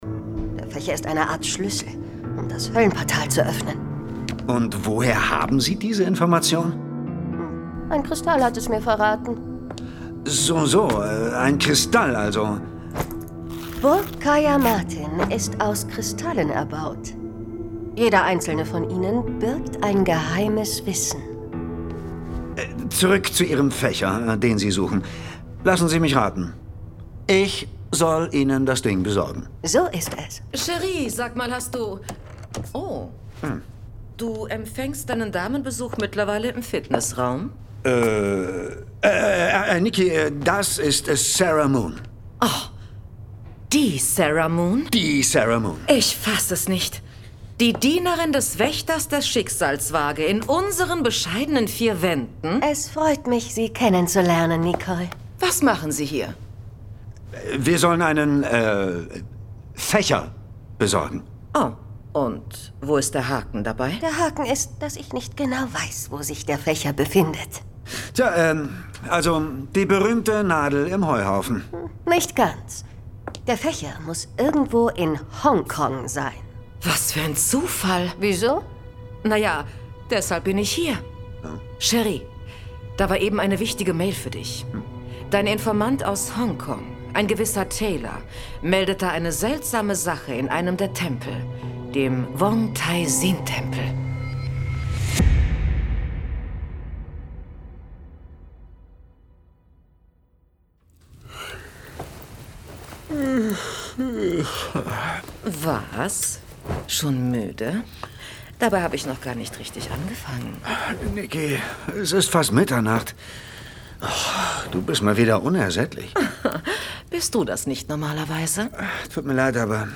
Professor Zamorra - Folge 10 Fächer der Unsterblichen. Hörspiel.